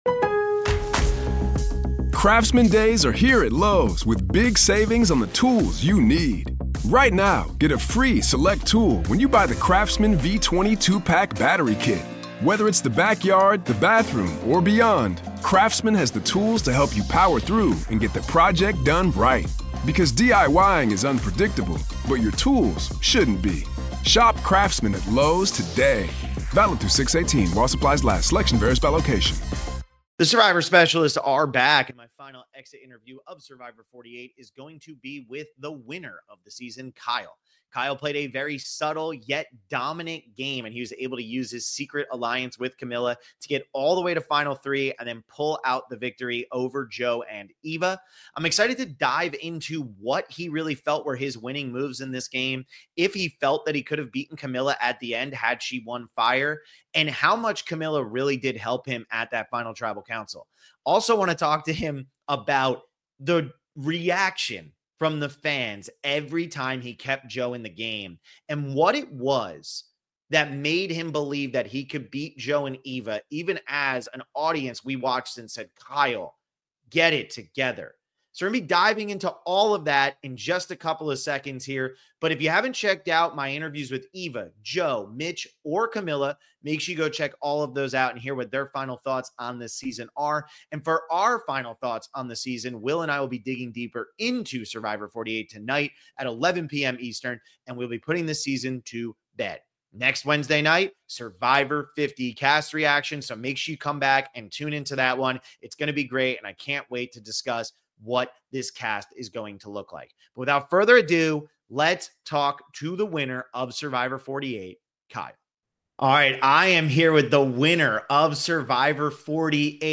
Survivor 48 Post Game Interview w